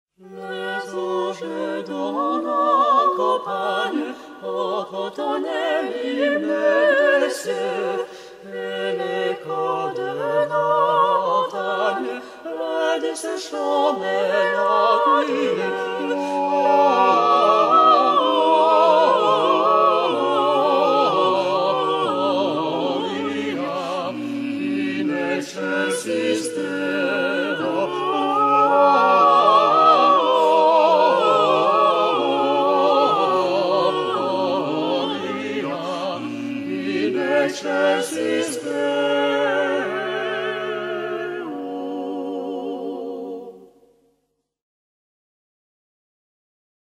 Les plus beaux chants de Noël servis par de grandes voix d’opéra !
Récital A Capella tout public
par le Manège Lyrique